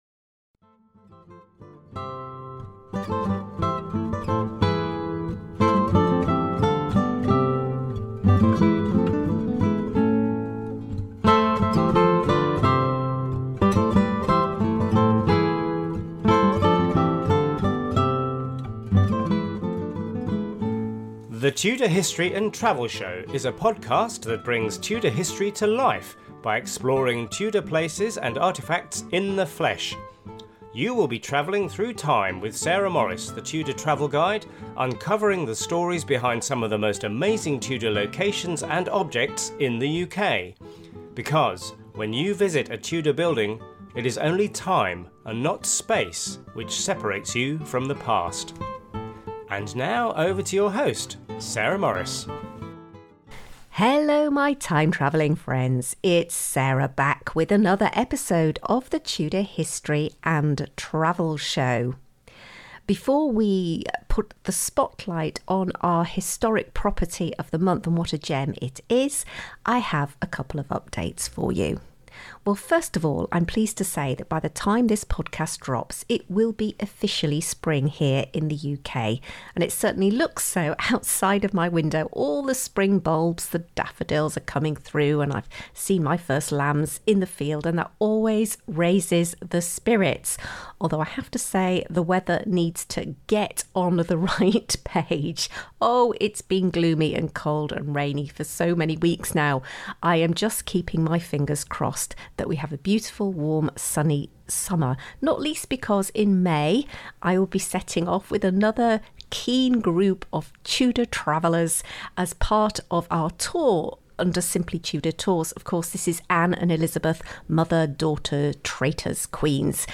Note : This is a show notes page accompanying my on-location podcast, recorded in spring 2025.